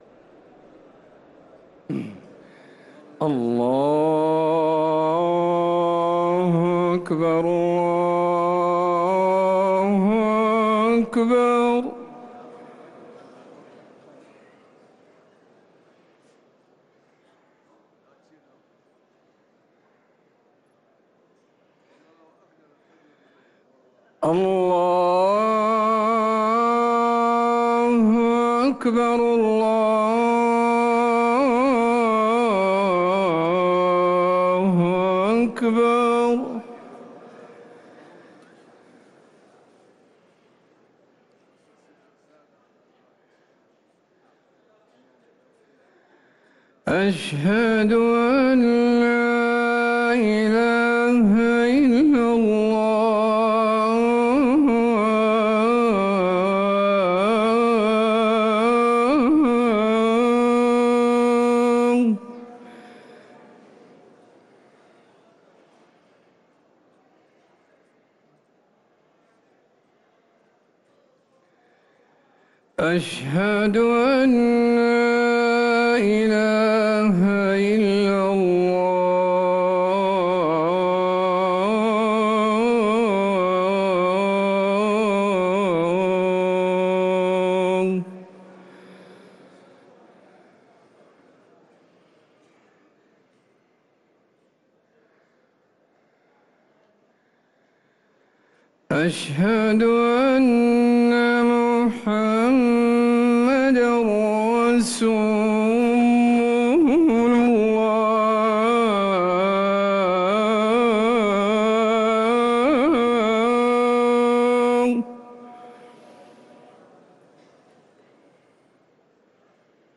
أذان العشاء
ركن الأذان